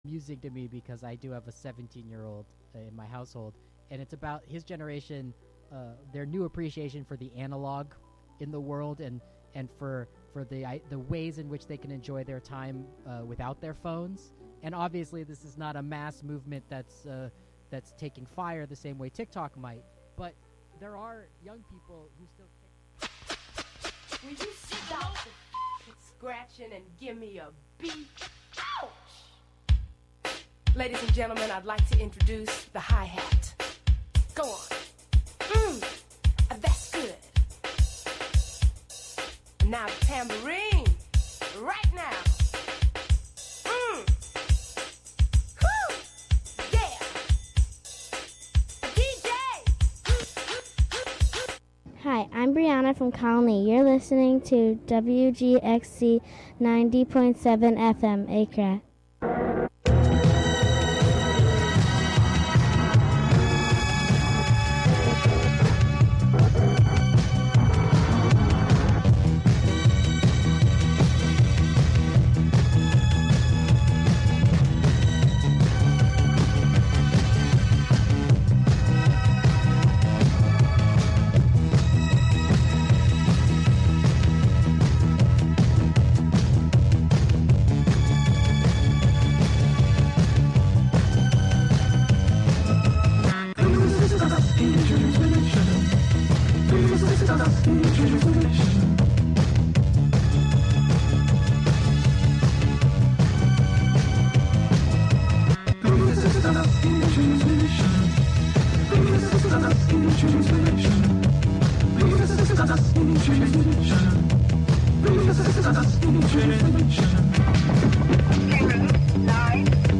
Tune in the latest episode of the radio serial "I Have Seen Niagara" with S3 EP6 - Coda- Beyond the Western Door: Overheard at the Oracle Feed n' Seed. Lets take a detour to the Oracle Feed n' Seed on the corner of Packard and Porter.